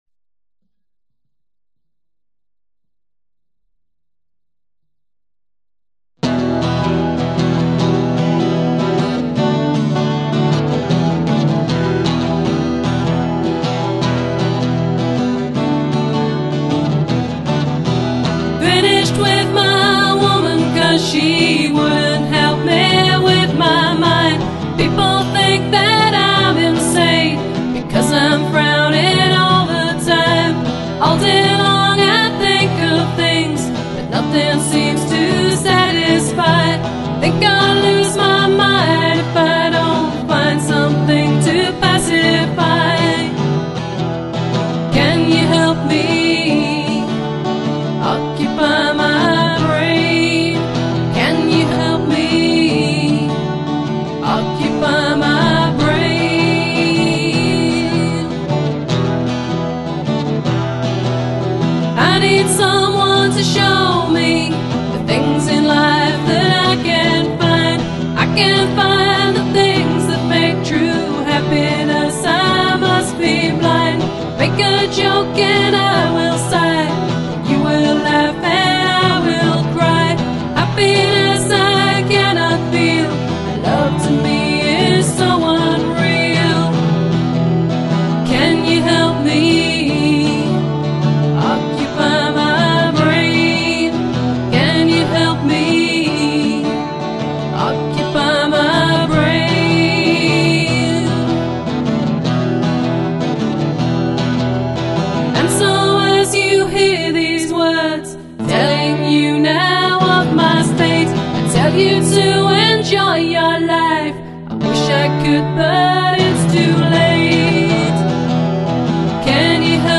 Mellow, Acoustic, Soul Searching Sounds...
acoustic cover